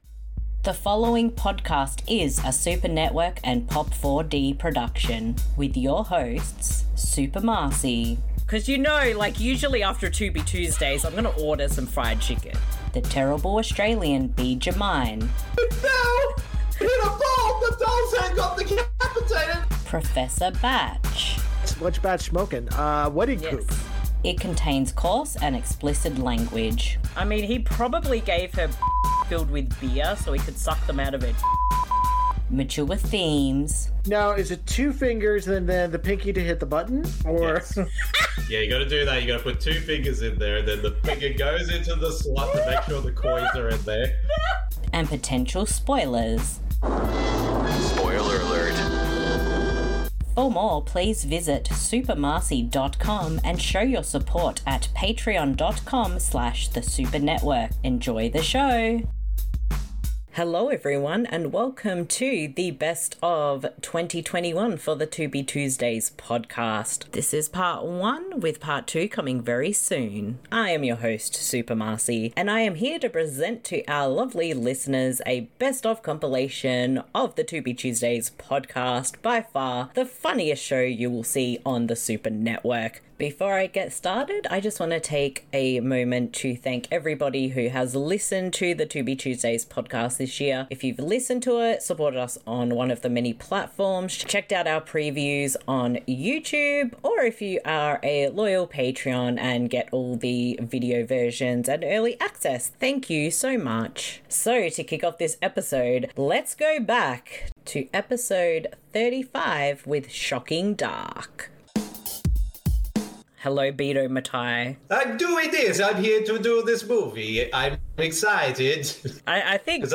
Hello every and welcome to Part 1 of our 2 part special with a best of 2021 compilation of funny and memorable moments for The Tubi Tuesdays Podcast for 2021!